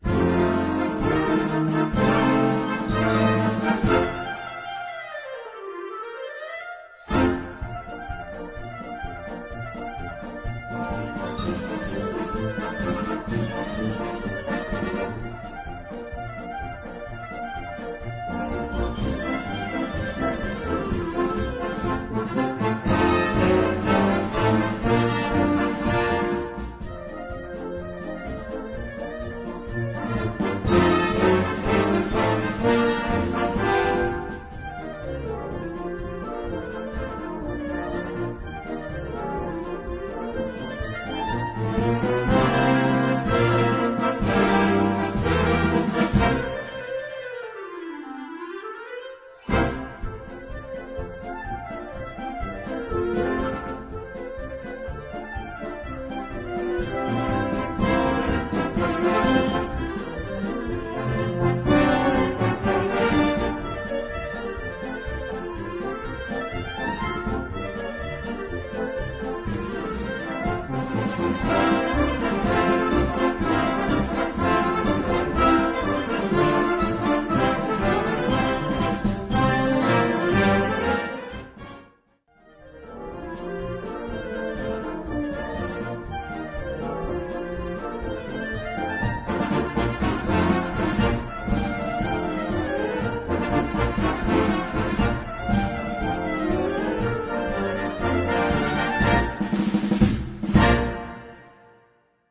Gattung: Solo für Klarinettenregister und Blasorchester
Besetzung: Blasorchester
Die Begleitung dazu ist relativ einfach gehalten.